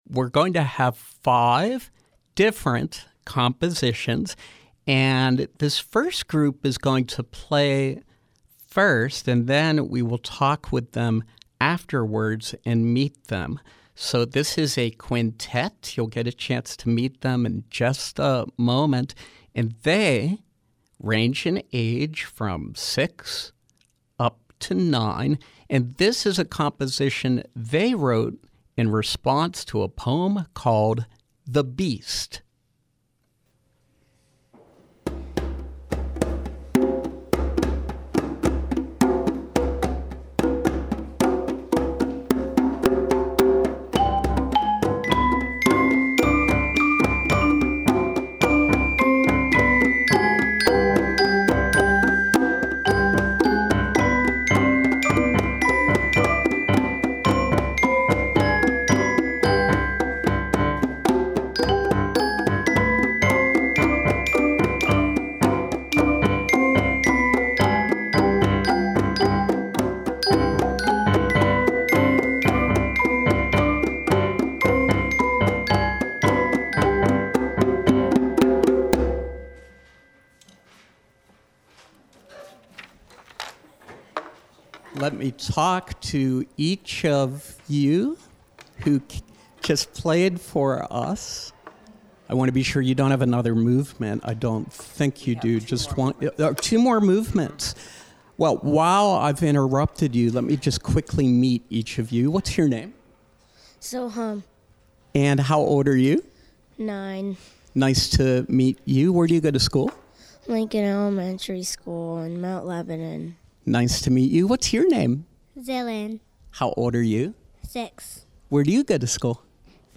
Five groups of students (ages 6 to 15)
keyboard, marimba, glockenspiel, and congas